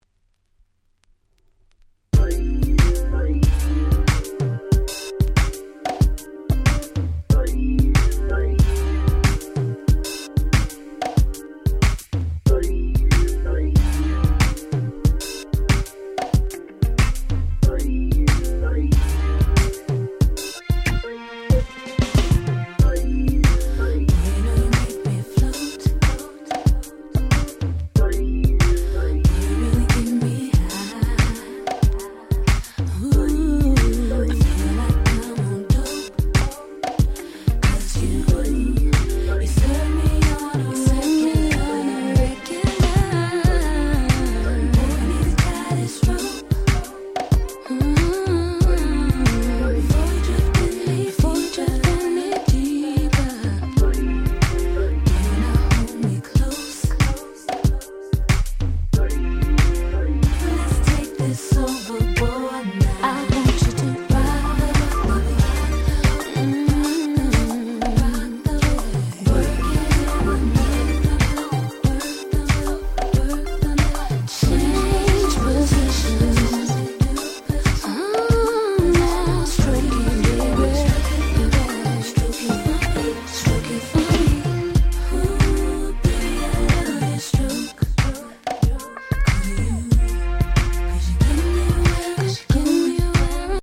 DJに使い易い様にIntro付きに改良した